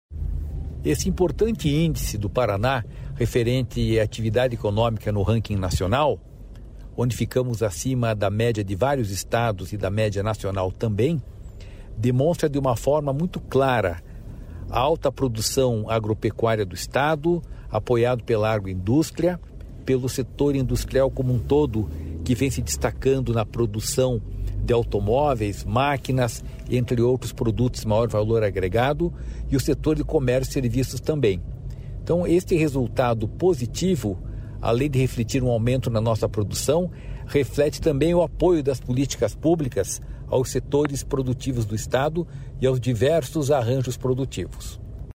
Sonora do presidente do Ipardes, Jorge Callado, sobre o Paraná liderar o crescimento da atividade econômica entre os estados